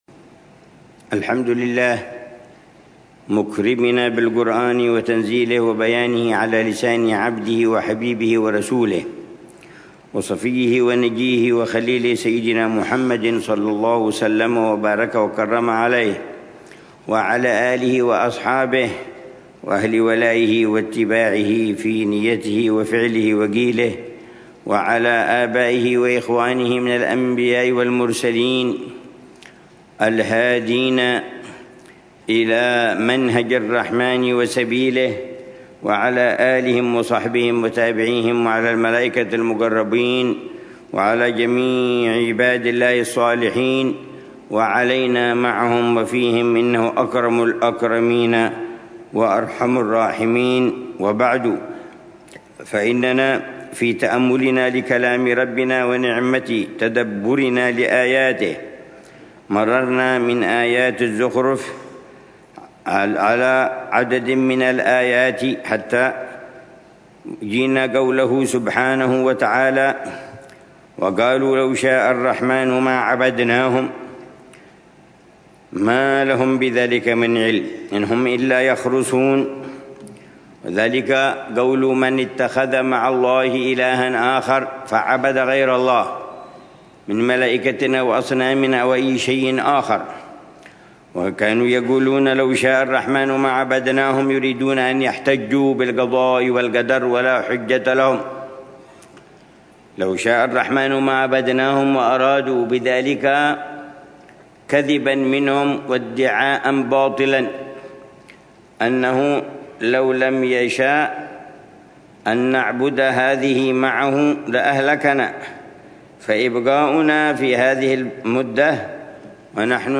ضمن الدروس الصباحية لشهر رمضان المبارك من عام 1446هـ